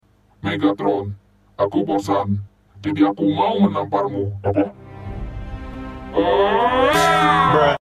Dubbing Indonesia